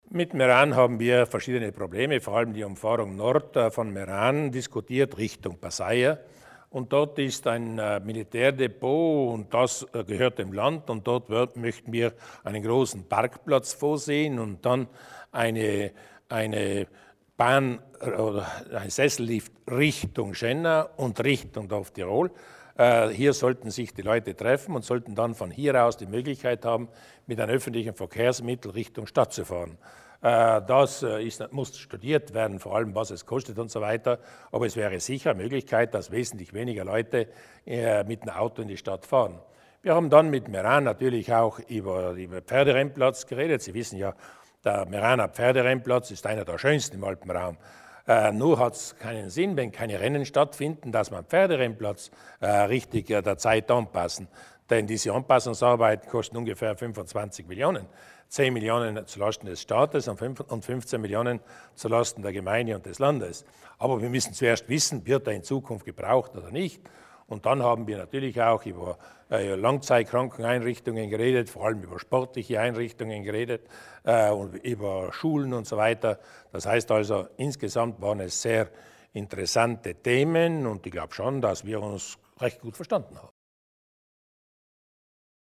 Landeshauptman Durnwalder erläutert die Projekte für Meran